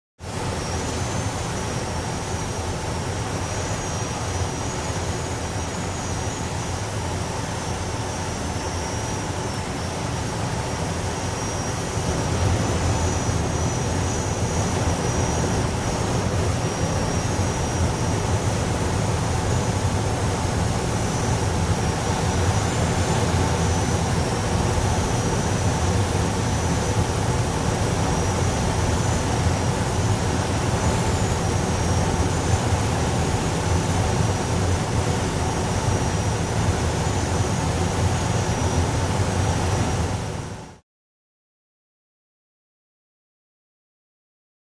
Glider, Interior Perspective; In Flight, Steady Wind Roar, More Whistling Than FX 26.